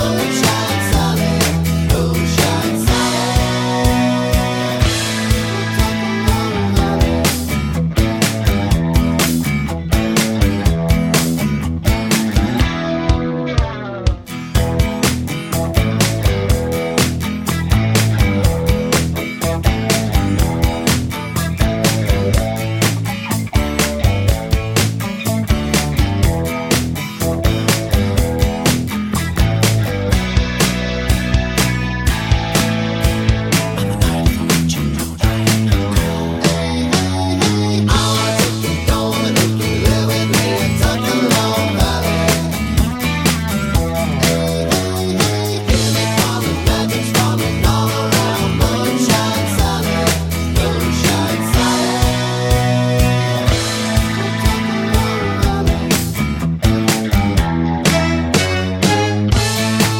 no Backing Vocals Glam Rock 3:23 Buy £1.50